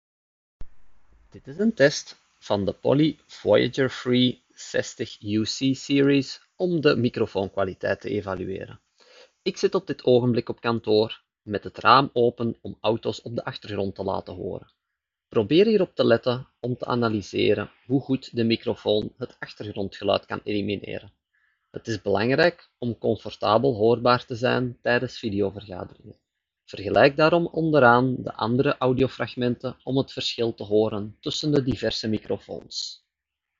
As tastes differ, we have provided below a series of sound clips in which we test the microphone of a webcam, laptop, speakerphone, headset and earphone, among others.
Poly Voyager Free 60+ (wireless headphones):